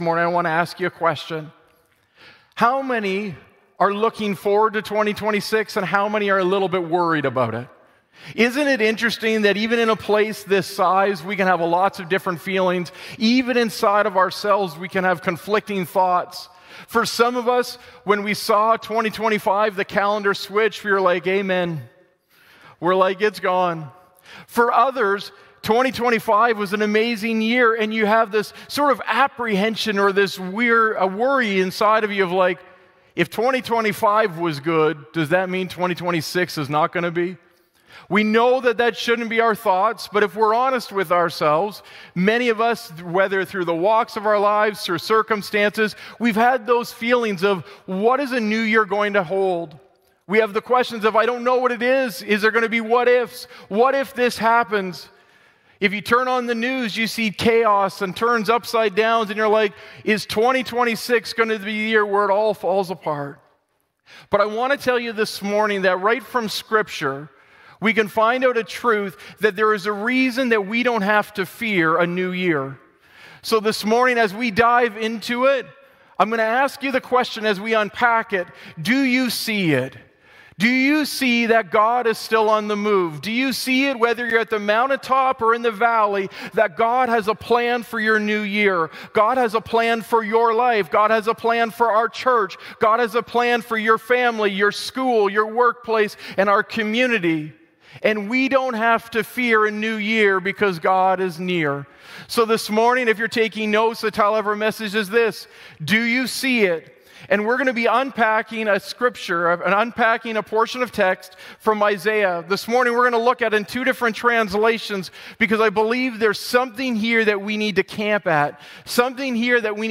Sermon Podcast Do You See It?